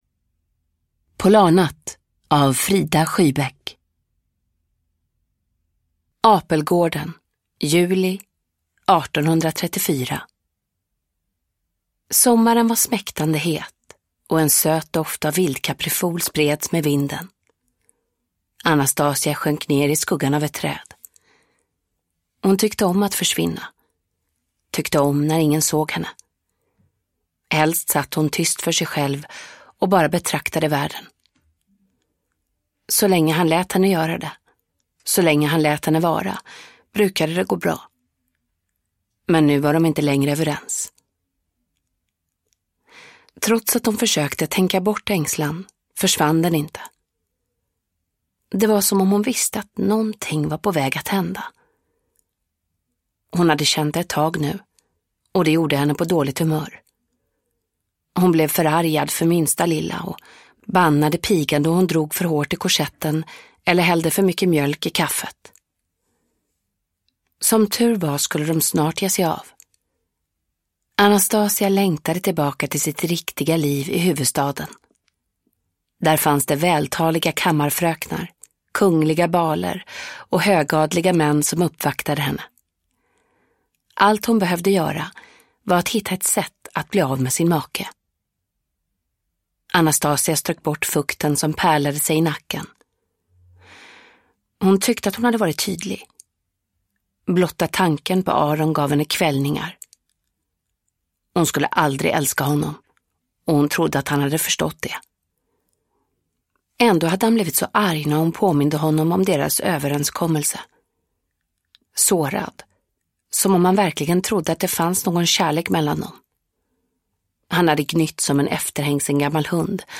Polarnatt – Ljudbok – Laddas ner
Uppläsare: Mirja Turestedt